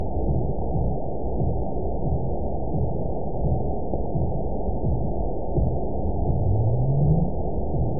event 920574 date 03/30/24 time 23:17:42 GMT (1 year, 1 month ago) score 9.50 location TSS-AB02 detected by nrw target species NRW annotations +NRW Spectrogram: Frequency (kHz) vs. Time (s) audio not available .wav